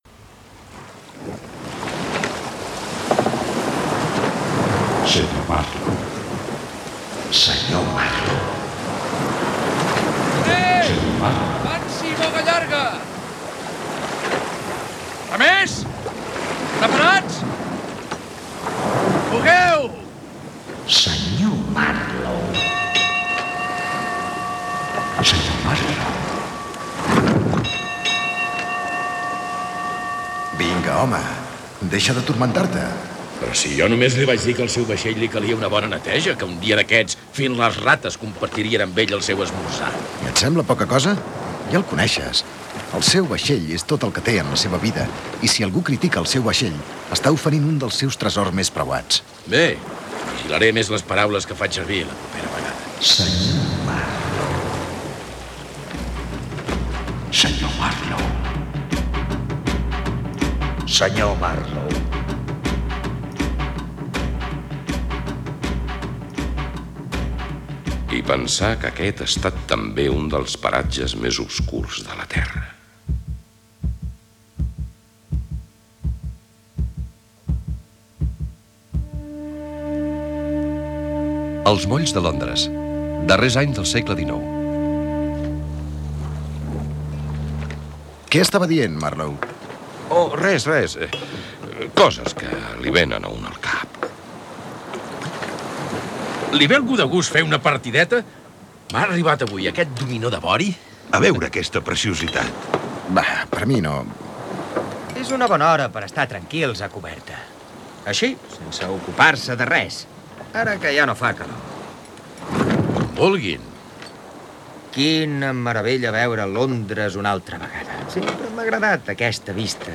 Adaptació radiofònica de "El cor de les tenebres "("Heart of Darkness"), de Joseph Conrad.
Ficció